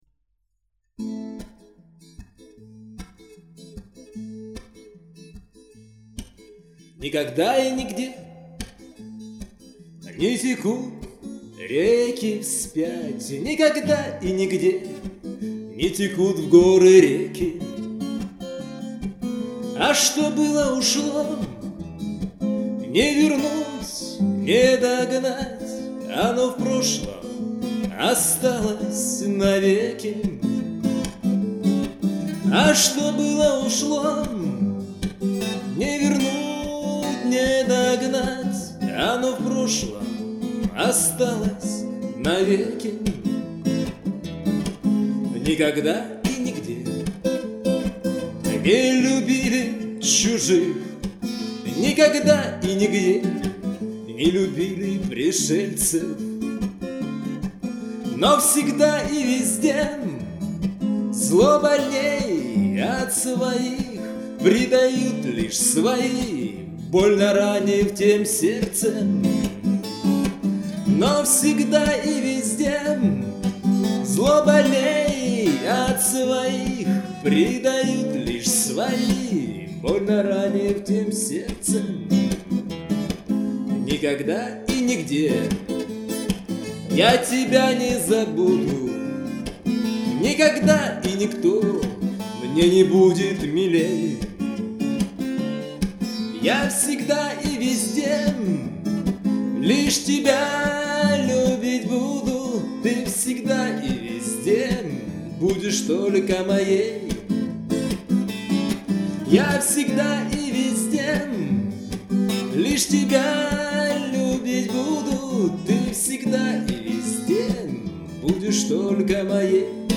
Авторские песни